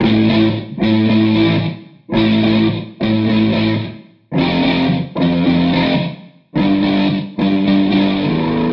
Rammfire Metal Rhythm Am F G
描述：警告非常响亮，听之前请调低音量。 Rammfire英国双12金属放大器。 压缩和小混响。我不是一个金属吉他手，所以它可能不是很正确。
Tag: 110 bpm Heavy Metal Loops Guitar Electric Loops 1.47 MB wav Key : A